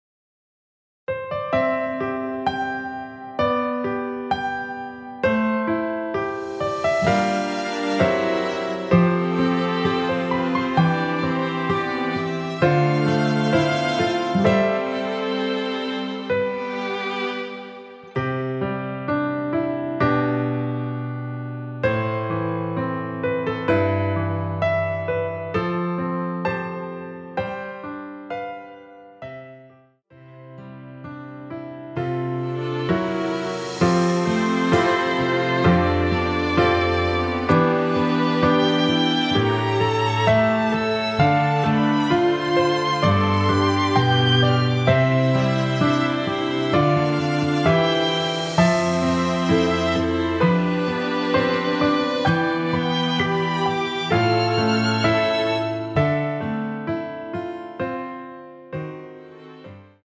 노래방에서 음정올림 내림 누른 숫자와 같습니다.
앞부분30초, 뒷부분30초씩 편집해서 올려 드리고 있습니다.
중간에 음이 끈어지고 다시 나오는 이유는